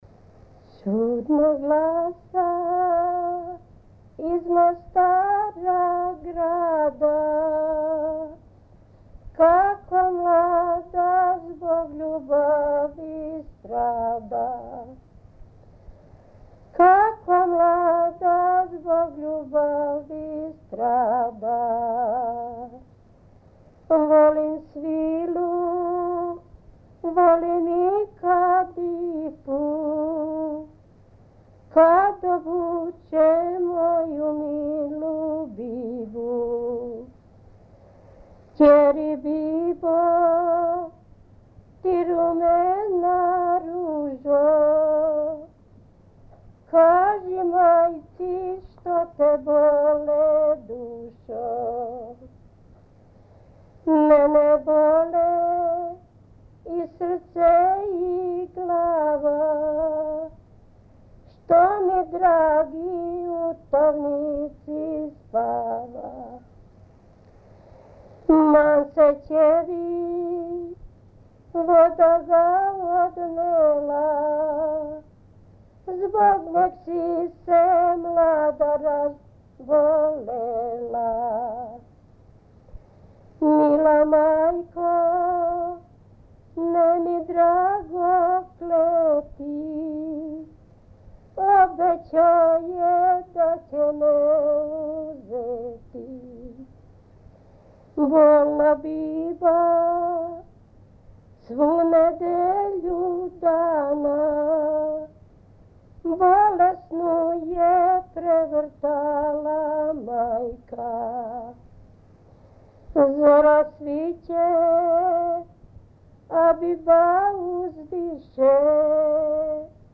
Тема: Песме народа Југославије
Снимљено у Пестсзентлőринцу (Будимпешта) – Приповедна песма